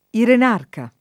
vai all'elenco alfabetico delle voci ingrandisci il carattere 100% rimpicciolisci il carattere stampa invia tramite posta elettronica codividi su Facebook irenarca [ iren # rka ] o irenarco [ iren # rko ] s. m. (stor.); pl.